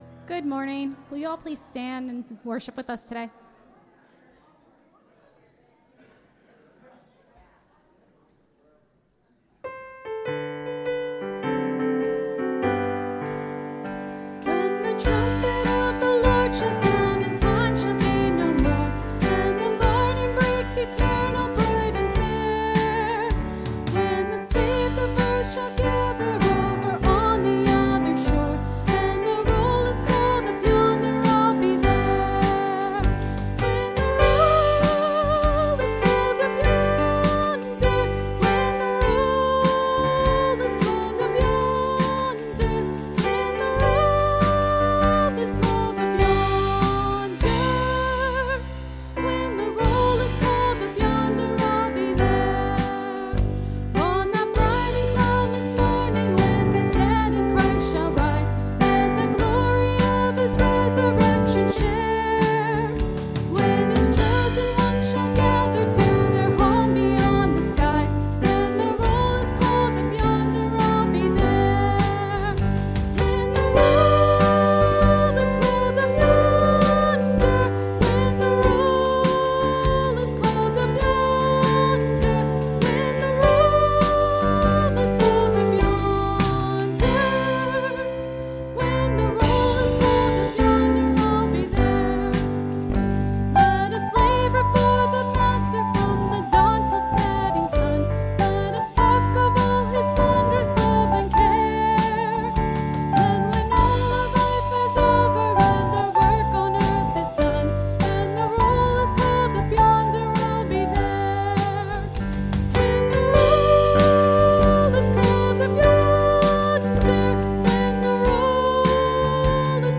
(Sermon starts at 25:25 in the recording).